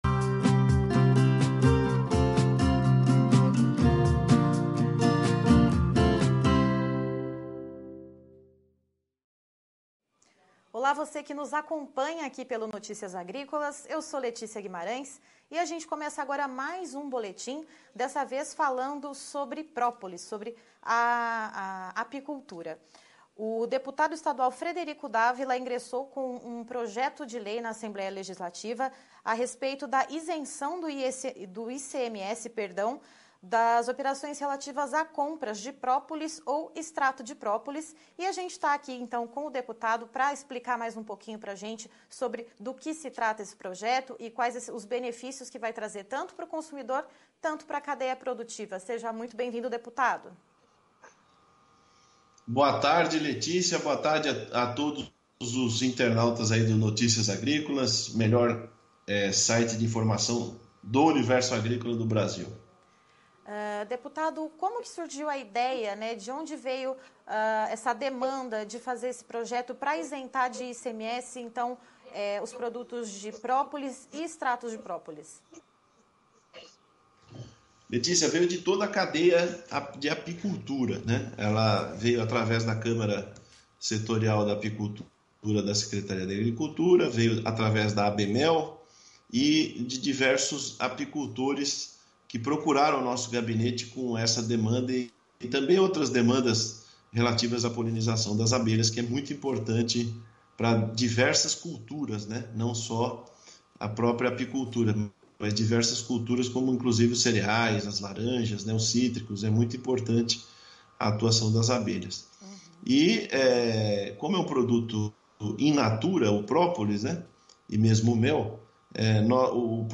Entrevista com Frederico D´Avila - Deputado Estadual - PSL - SP sobre a Isenção dos imposto sobre o própolis